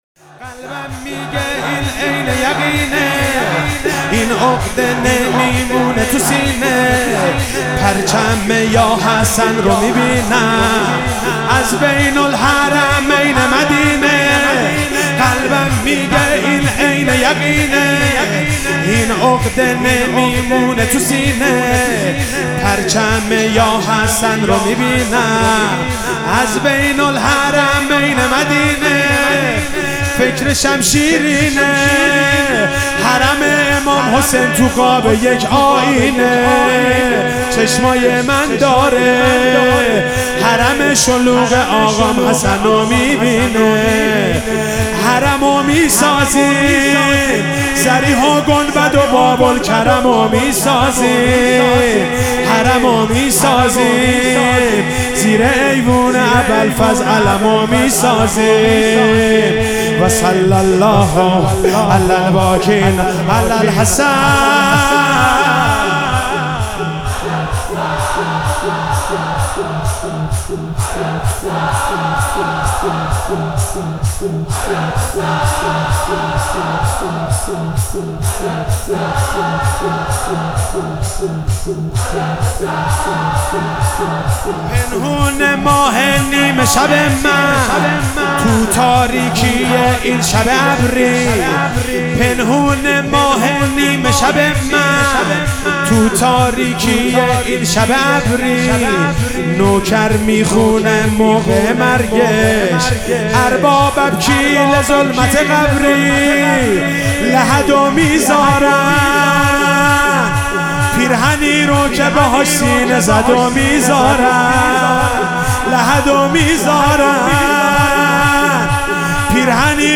شور فوقالعاده قلبم میگه این عین یقینه
(محرم الحرام 1398)
هیئت انصار الحجه مشهد مقدس ---------------------------------------------- برای شنیدن کارهای بیشتر روی تگ اسم